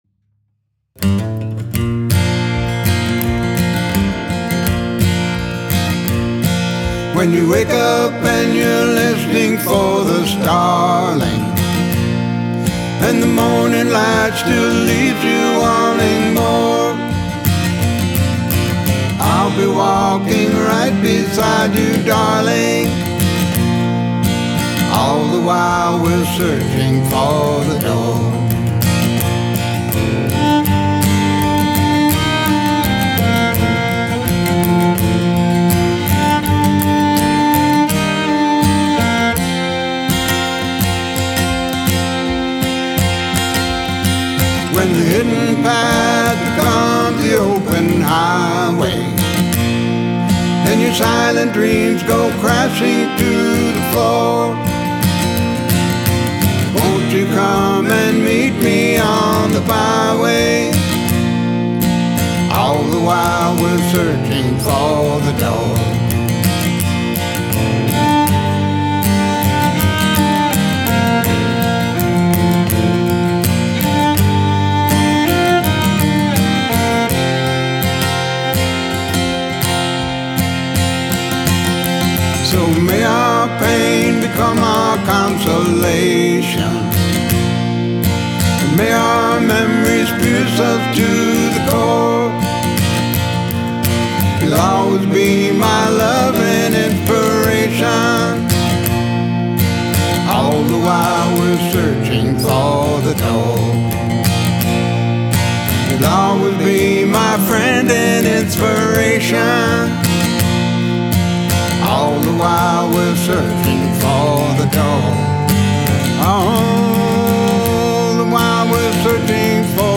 Guitar, Mandolin, and Harmonica
Cello, Piano, and Organ